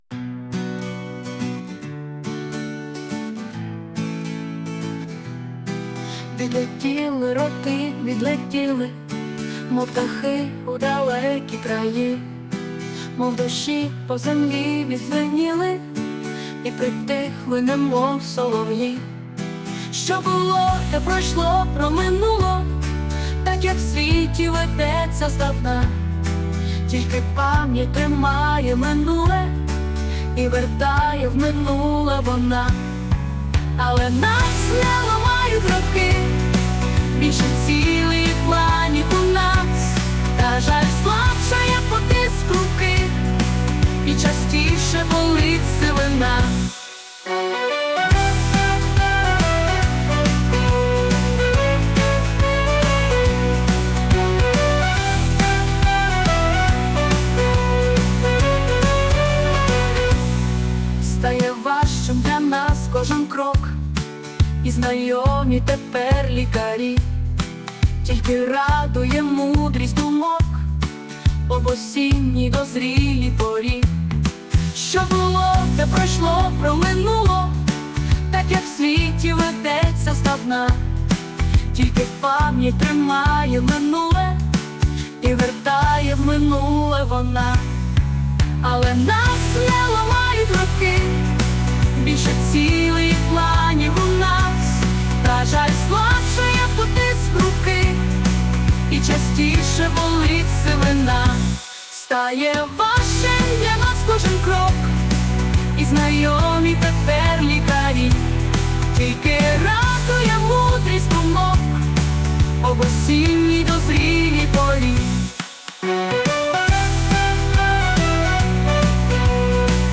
СТИЛЬОВІ ЖАНРИ: Ліричний
Гарна пісня, яка досягає чуттєвої вершини.